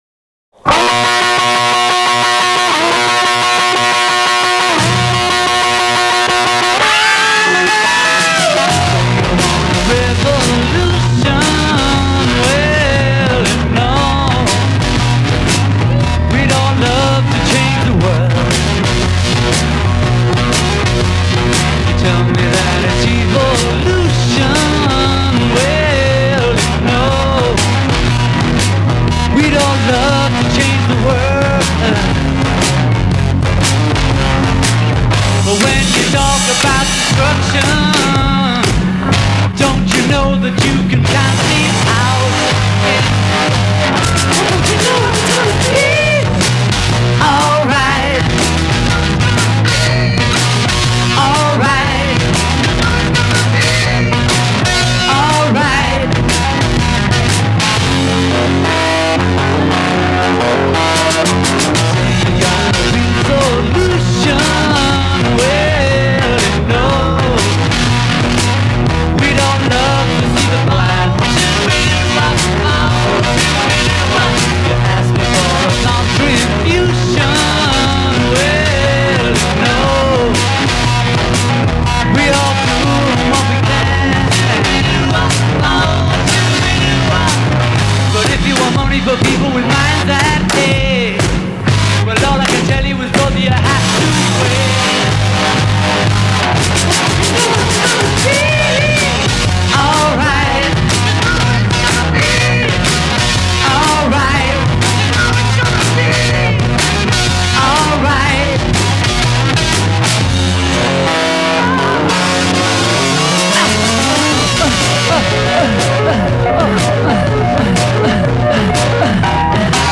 rockowa piosenka